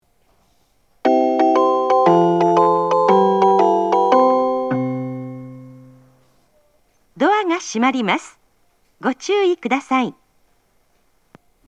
発車メロディー
一度扱えばフルコーラス鳴ります。
こちらは行き違い無しなら録りやすいです。